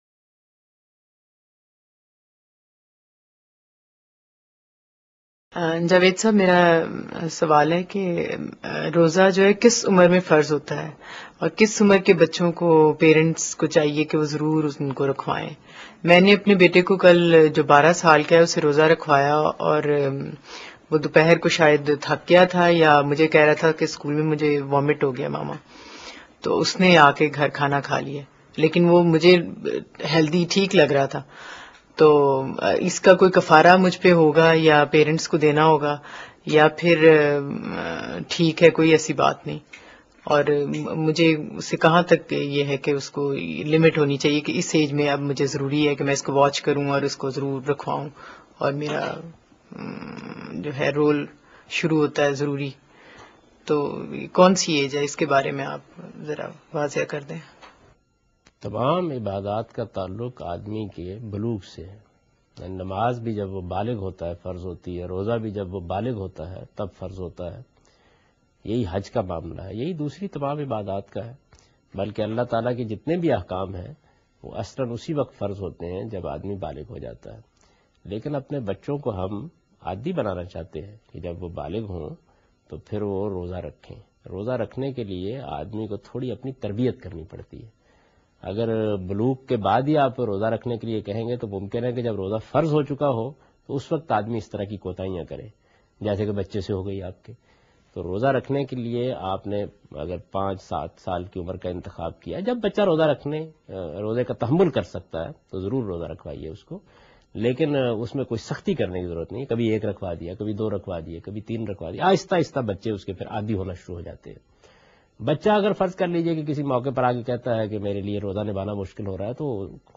جاوید احمد غامدی کس عمر میں بچوں کو روزہ رکھناچاہیے کے متعلق بیان کر رہے ہیں